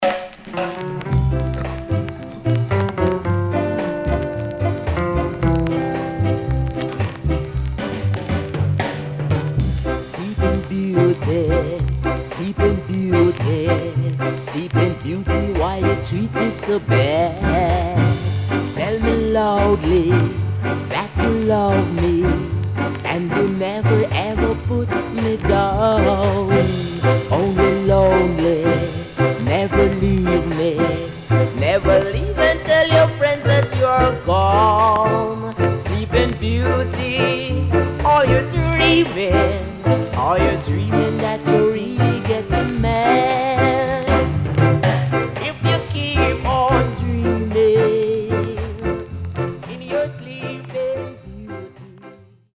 CONDITION SIDE A:VG〜VG(OK)
W-Side Good Rocksteady Vocal
SIDE A:所々チリノイズ、プチノイズ入ります。